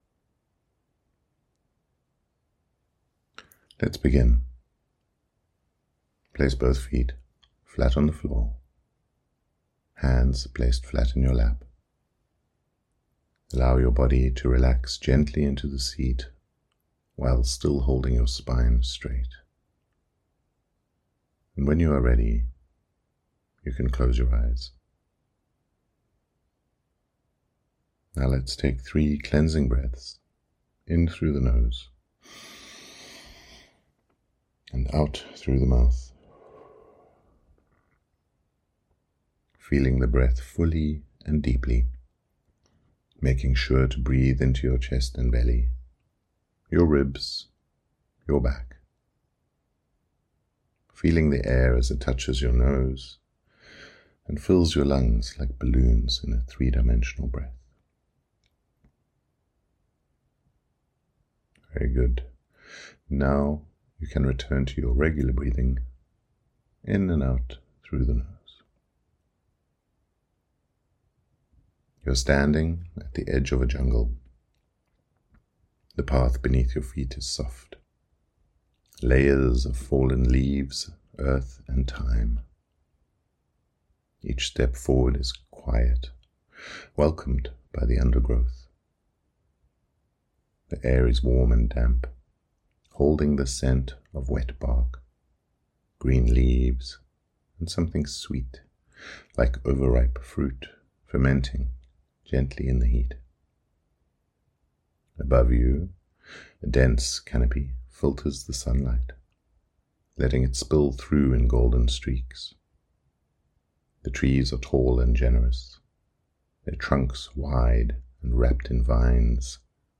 Tin taNgle Meditation
WS36-meditation.mp3